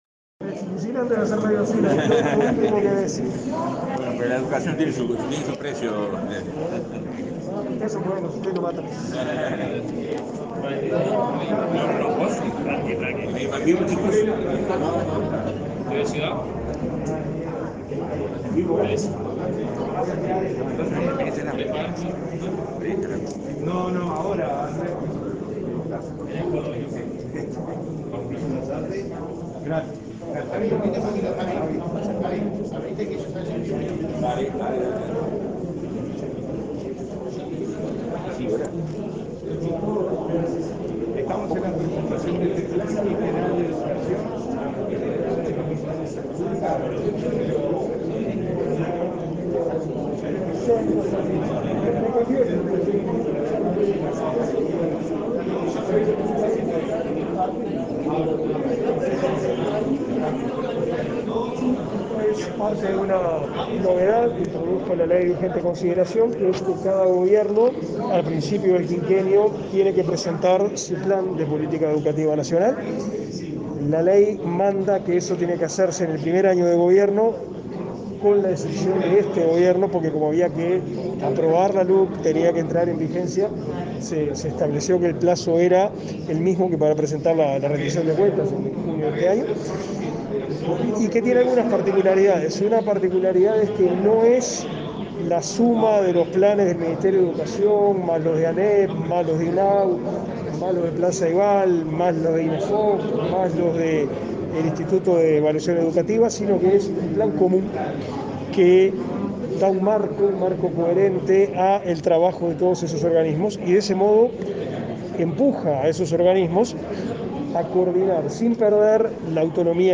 Declaraciones del ministro de Educación y Cultura, Pablo da Silveira
Declaraciones del ministro de Educación y Cultura, Pablo da Silveira 05/08/2021 Compartir Facebook X Copiar enlace WhatsApp LinkedIn Este jueves 5, en la sala Vaz Ferreira, el ministro de Educación y Cultura, Pablo da Silveira, brindó declaraciones a la prensa, tras presentar a la ciudadanía el Plan de Política Educativa Nacional 2020-2025.